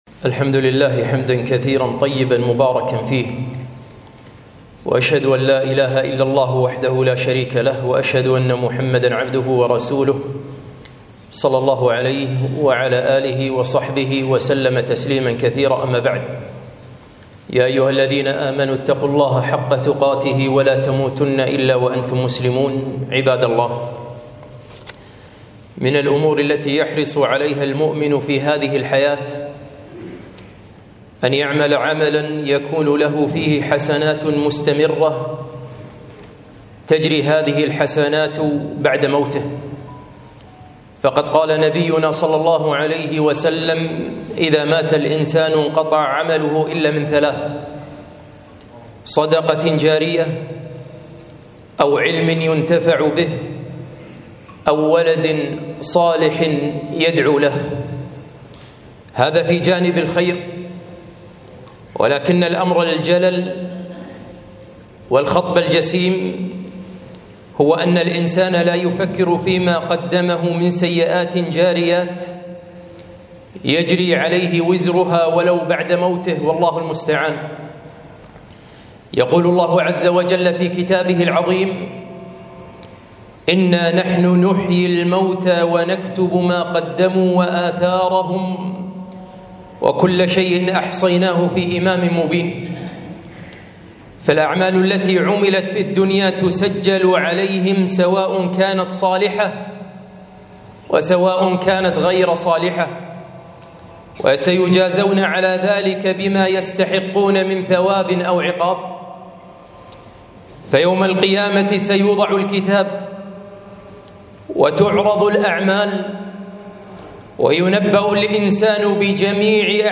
خطبة - السيئات الجارية